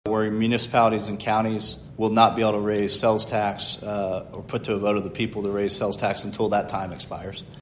CLICK HERE to listen to commentary from Greg Treat.